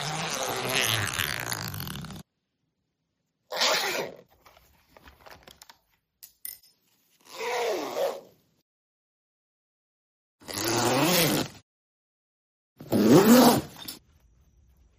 Busy Kitchen
Busy Kitchen is a free ambient sound effect available for download in MP3 format.
452_busy_kitchen.mp3